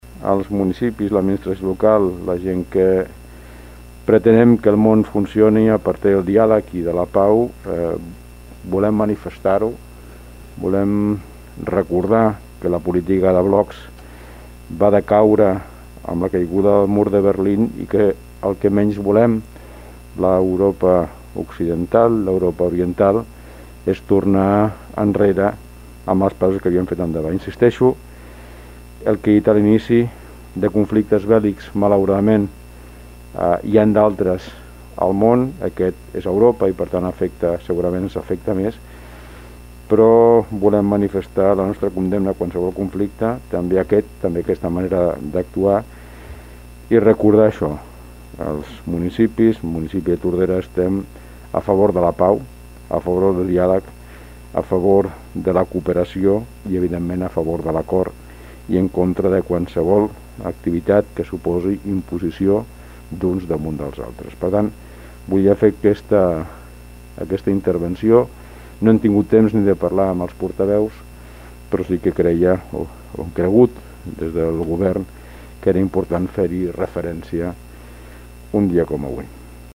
Ho va fer a l’inici del ple ordinari que es va celebrar aquest dijous al local de l’antic hospital de pobres.
Alcalde-ucraina.mp3